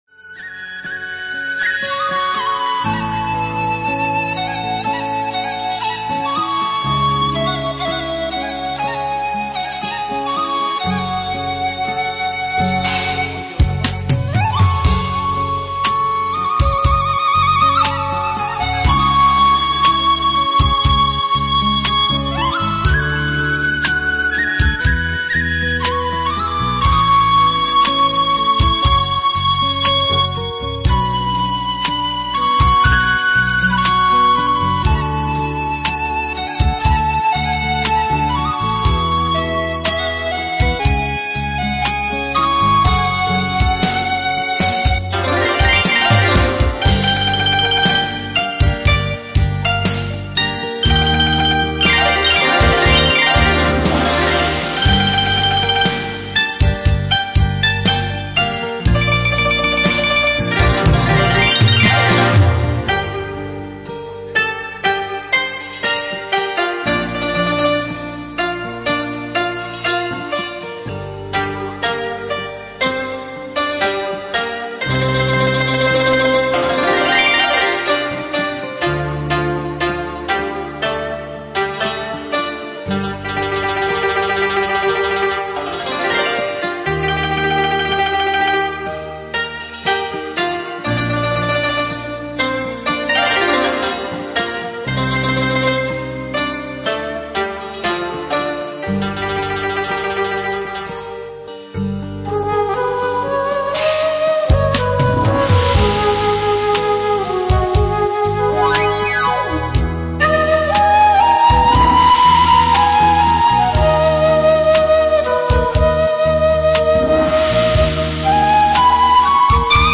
Mp3 Pháp Thoại Tọa Đàm Ăn Chay Và Sức Khỏe
tại Tu Viện Tường Vân